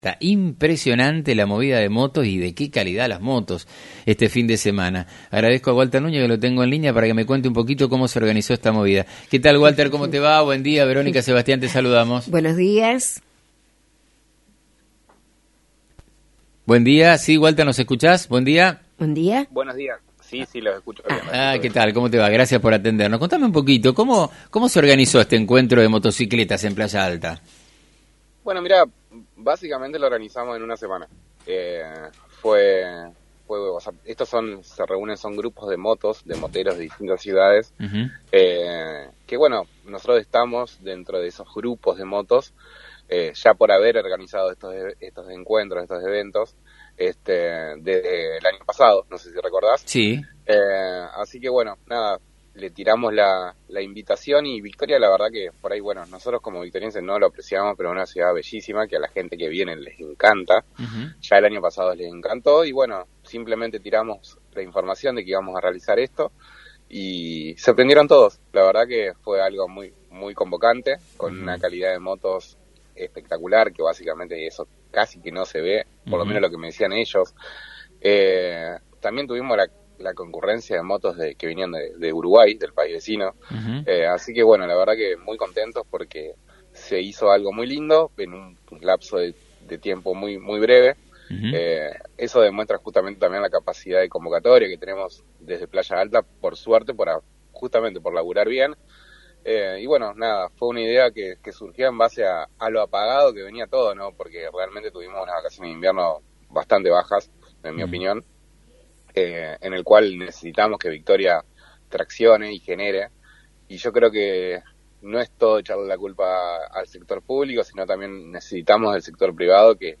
por FM90.3